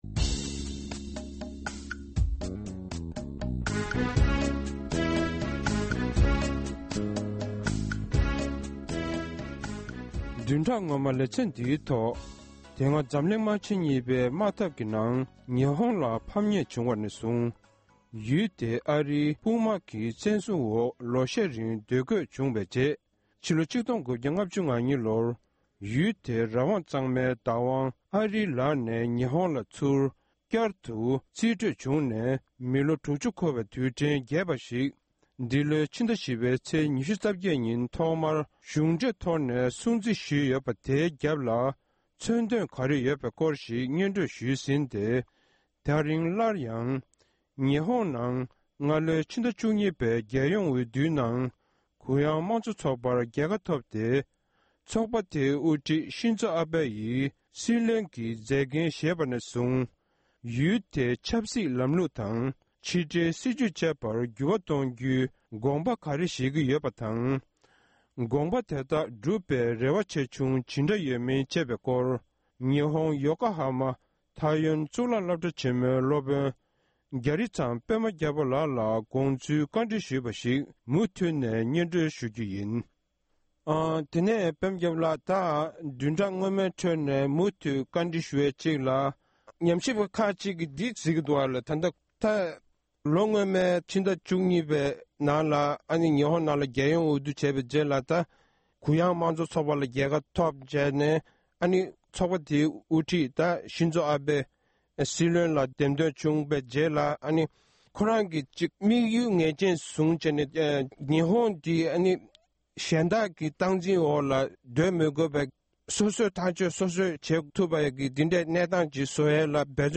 གནས་འདྲི་ཞུས་པར་མུ་མཐུད་ནས་གསན་རོགས༎